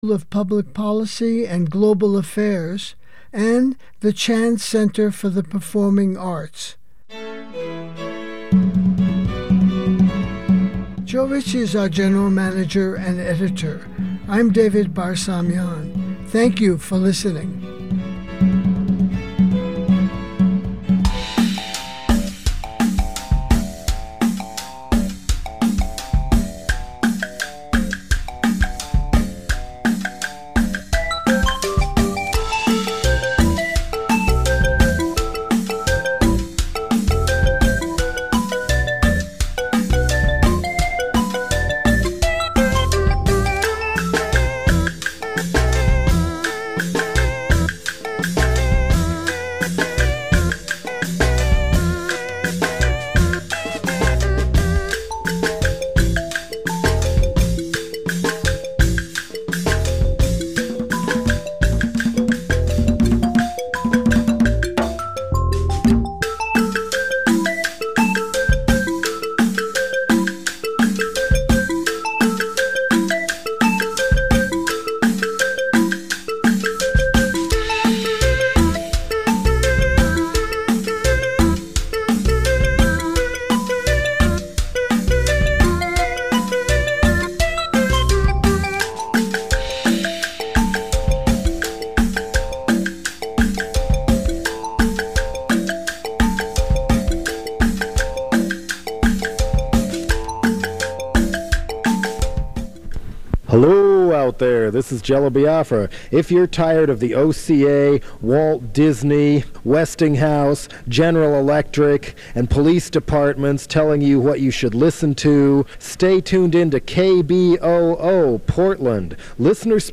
INVESTIGATIVE News Radio